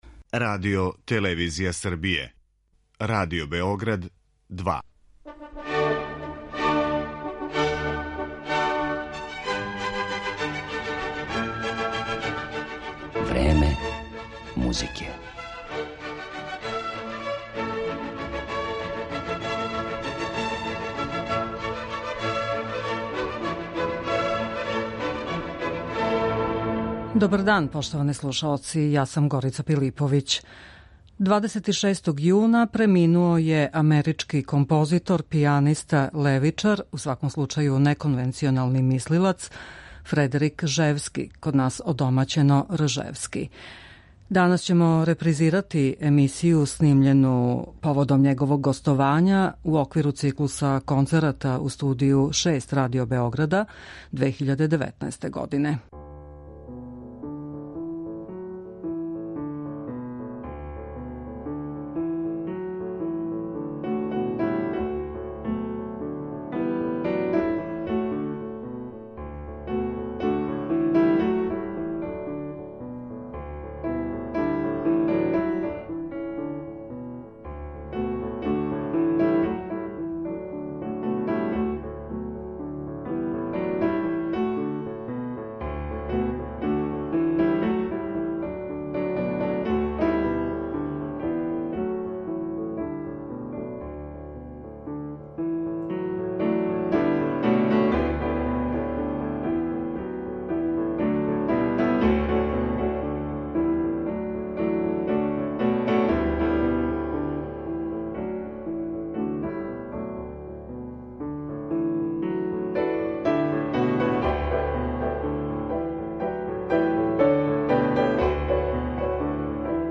Тим поводом репризирамо емисију Време музике снимљену 2019. године, када је Ржевски наступио у оквиру циклуса концерата у Студију 6 Радио-Београда.